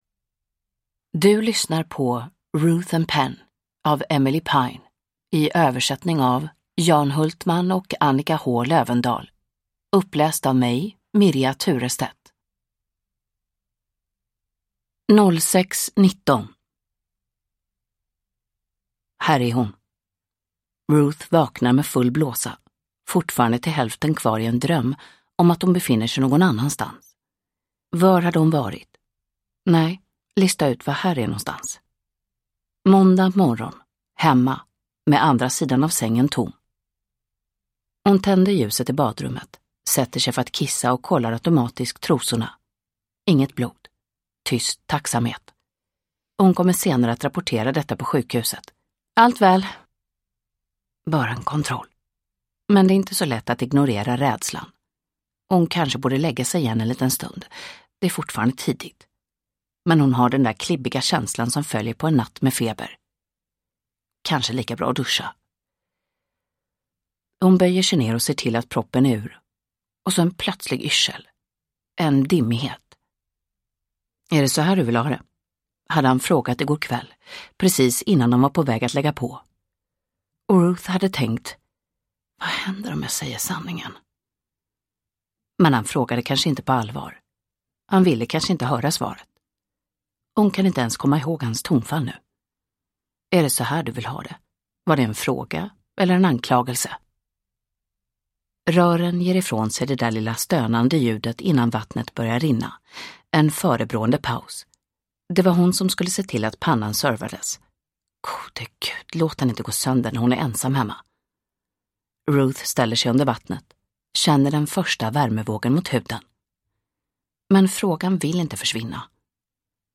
Ruth & Pen – Ljudbok – Laddas ner